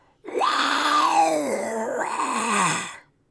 Zombie Voice Pack - Free / Zombie Aggressive
zombie_agressive_039.wav